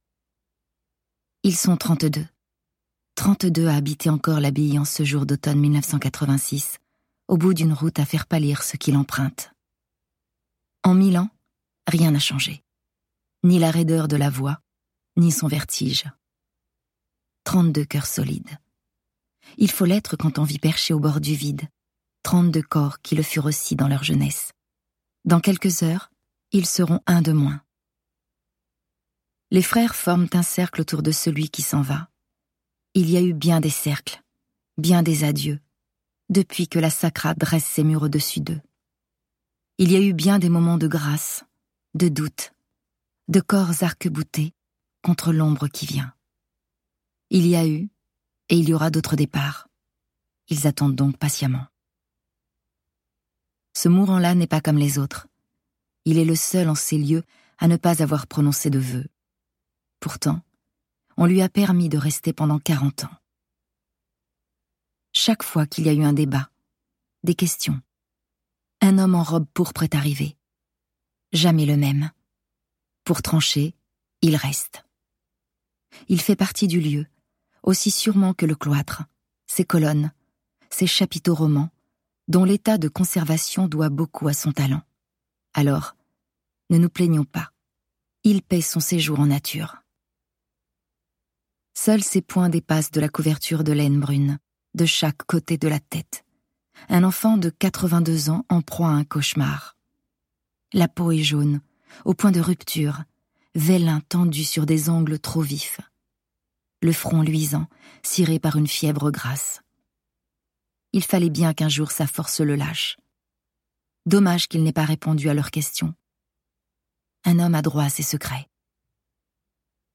Un livre audio plein de fougue et d'éclats, habité par la grâce et la beauté.